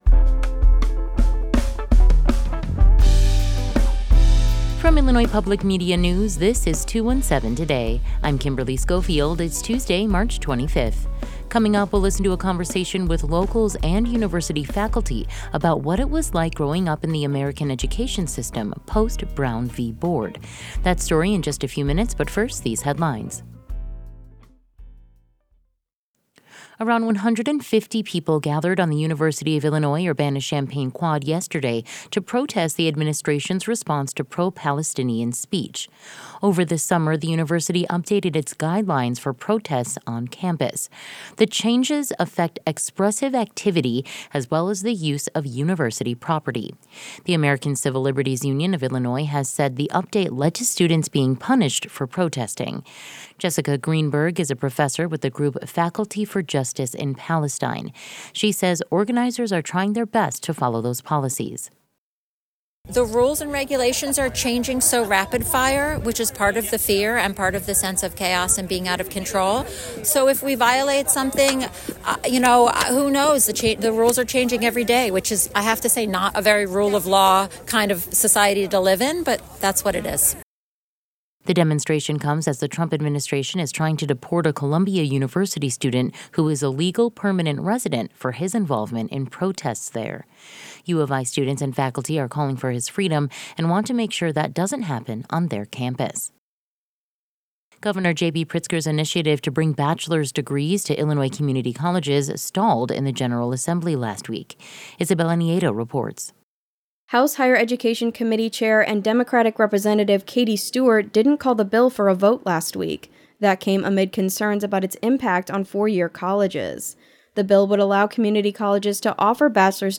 In today's deep dive, we'll listen to a conversation with locals and university faculty about what it was like growing up in the American education system post Brown v Board.